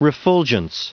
Prononciation du mot refulgence en anglais (fichier audio)
Prononciation du mot : refulgence